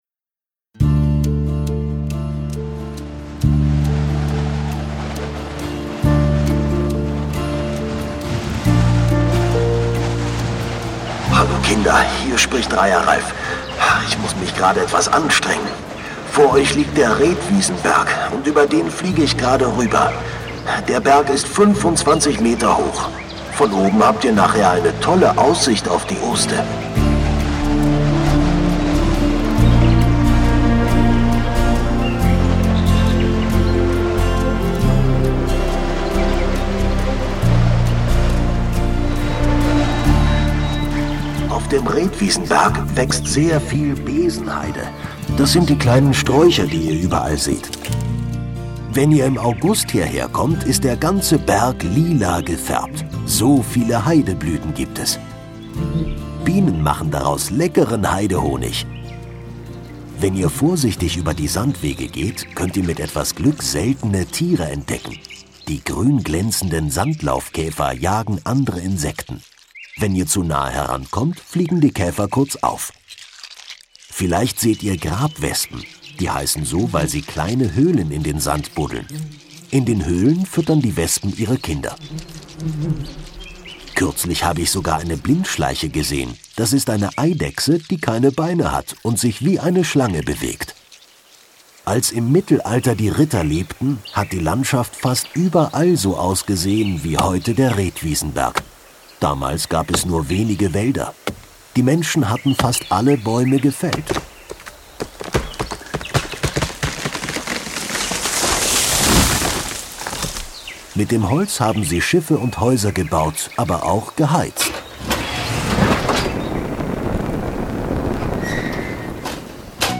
Heide - Rethwiesenberg - Kinder-Audio-Guide Oste-Natur-Navi